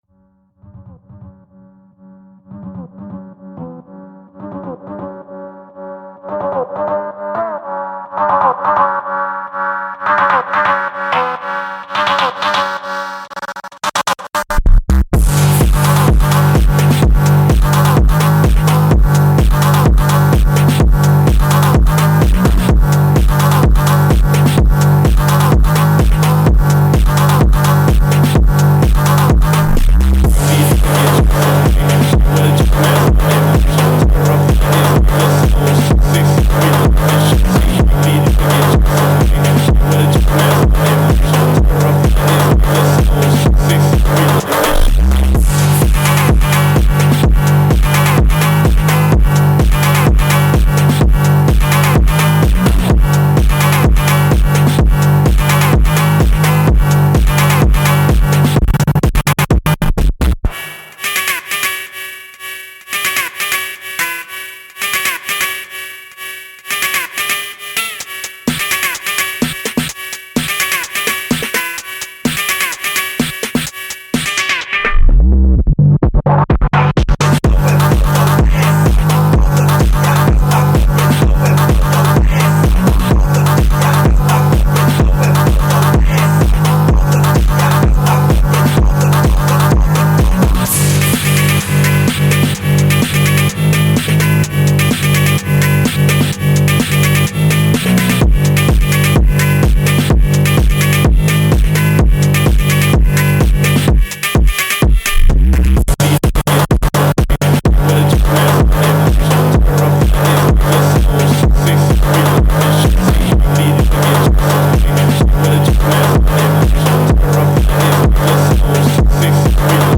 An Unreleased beat of mine :D